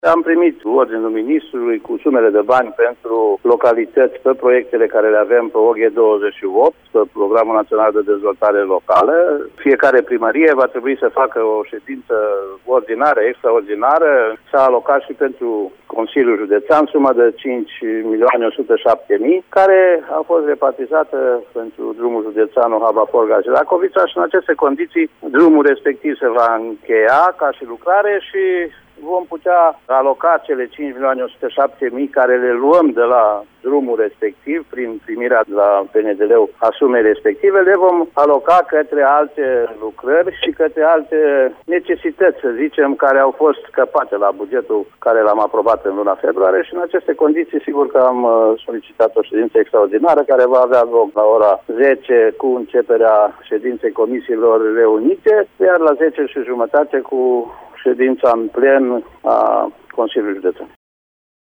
Preşedintele Consiliului Judeţean Timiş, Titu Bojin a declarat pentru postul nostru de radio că se va finaliza drumul Ohaba – Forgaci-Racoviţa, iar banii alocaţi acolo de forul judeţean vor fi redistribuiţi la alte lucrări.